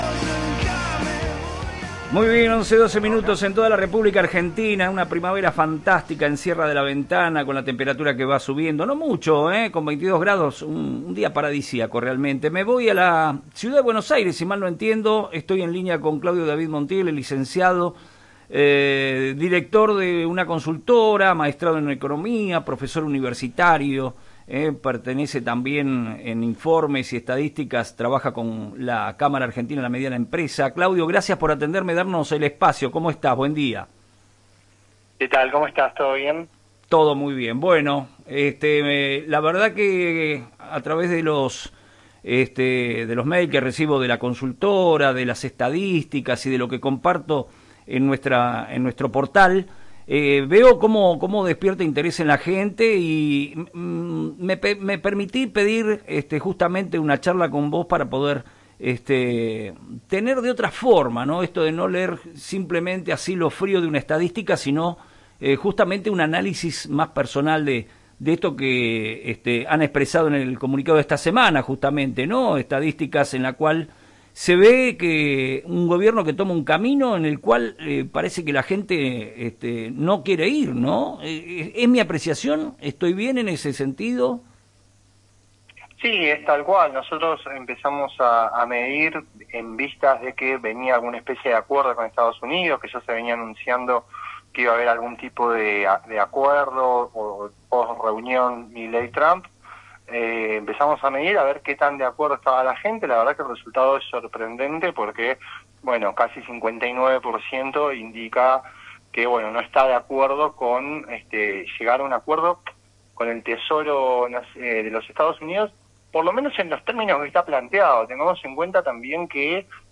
En diálogo con este medio, abordó el impacto del posible acuerdo con Estados Unidos, el desencanto social, el comportamiento electoral y las perspectivas económicas post elecciones.